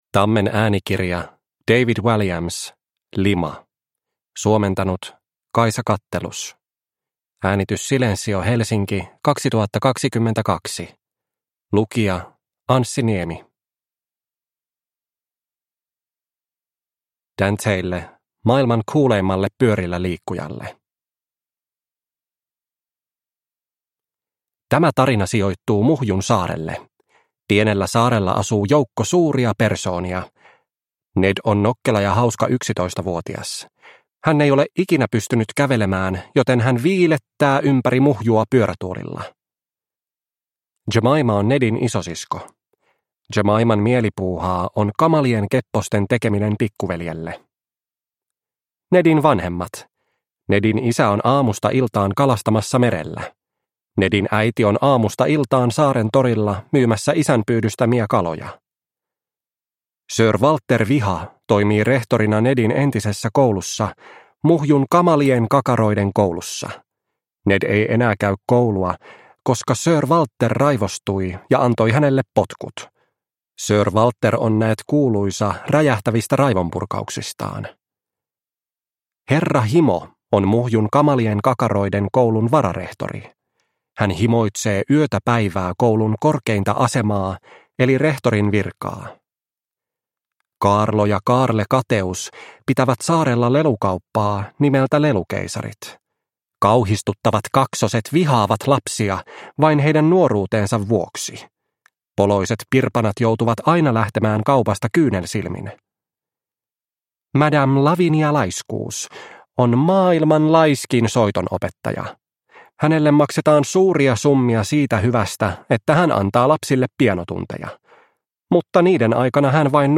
Lima – Ljudbok – Laddas ner